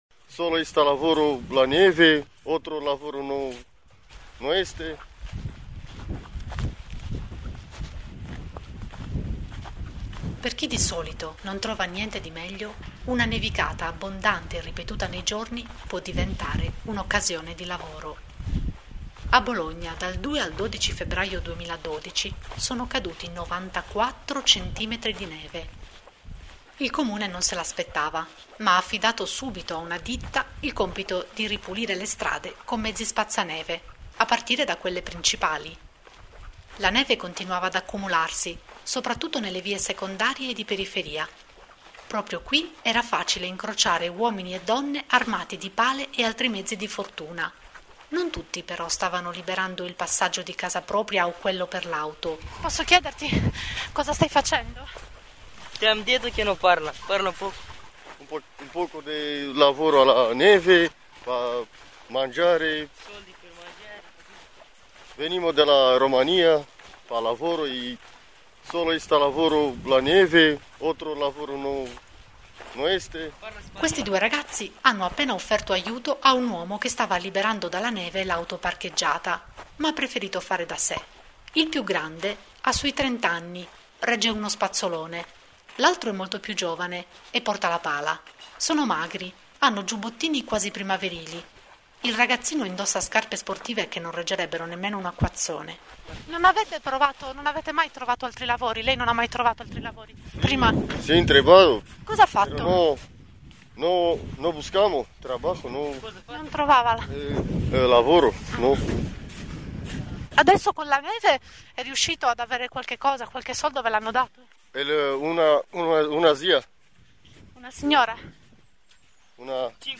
Un audioracconto di Rcdc vincitore al Premio Marco Rossi